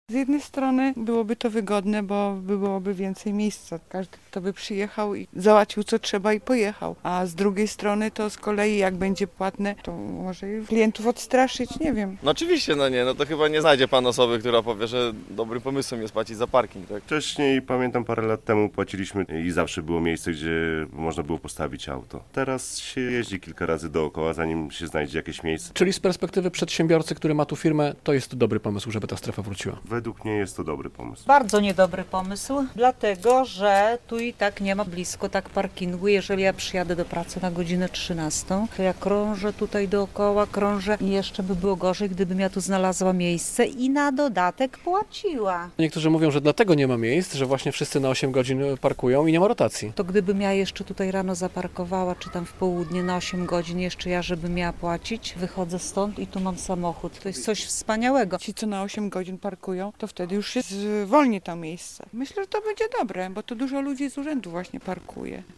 Opinie o strefie płatnego parkowania - relacja